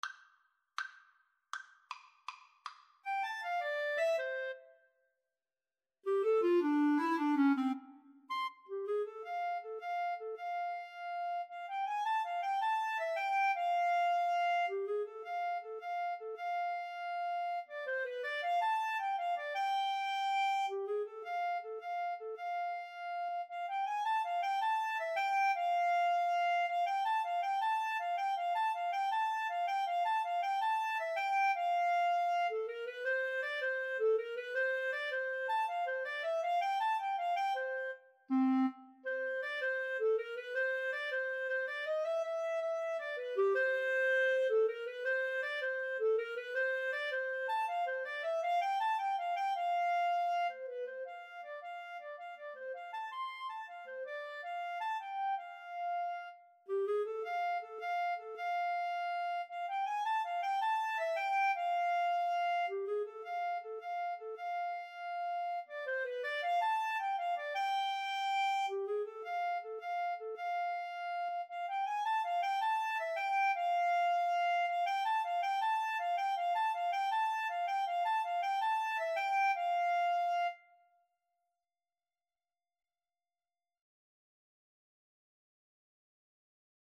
Free Sheet music for Clarinet-Cello Duet
F major (Sounding Pitch) G major (Clarinet in Bb) (View more F major Music for Clarinet-Cello Duet )
=180 Presto (View more music marked Presto)
Jazz (View more Jazz Clarinet-Cello Duet Music)